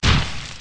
block_collapse.ogg